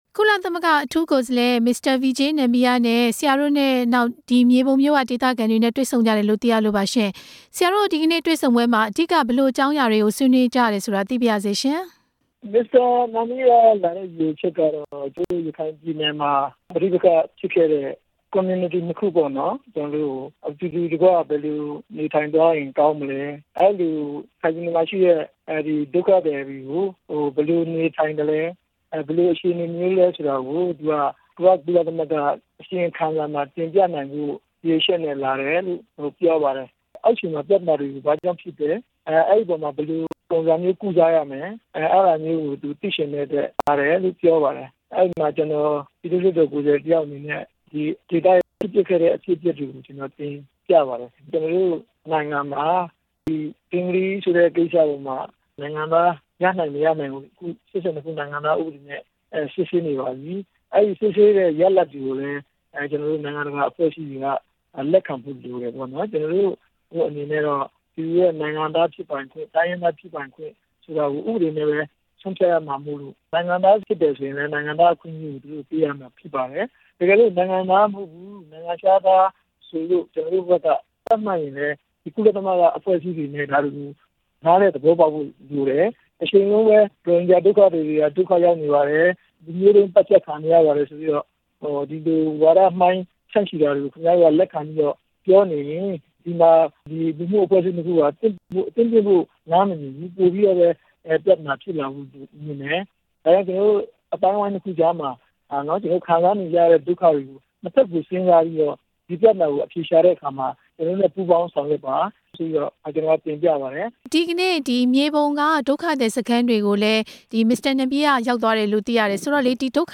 ဦးဖေသန်းနဲ့ ဆက်သွယ်မေးမြန်းချက်
Mr. Nambiar ရဲ့ မြေပုံမြို့ ခရီးစဉ်အကြောင်း လွှတ်တော်ကိုယ်စားလှယ် ဦးဖေသန်းကို ဆက်သွယ်မေးမြန်းထား တာ နားဆင်နိုင်ပါတယ်။